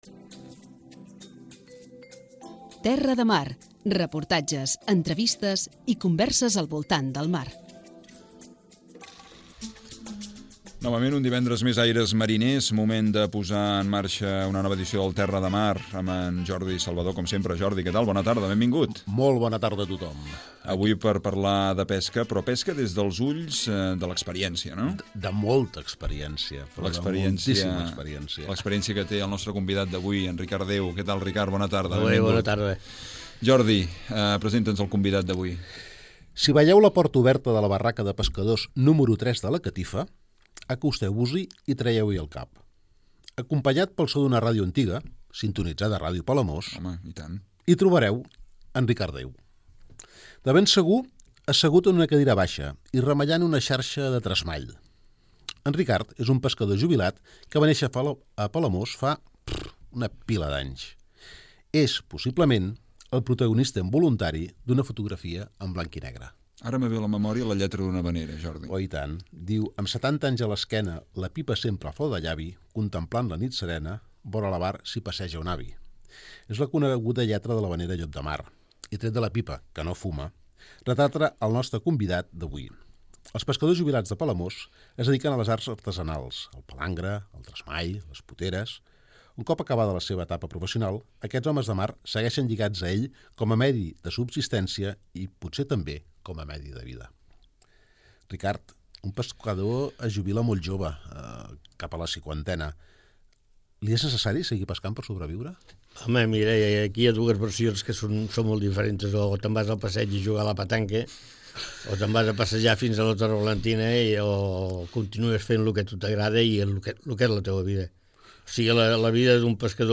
A banda de l'entrevista amb aquest pescador, repassarem les habituals seccions 'Paraules de Mar' i l'agenda marinera del cap de setmana.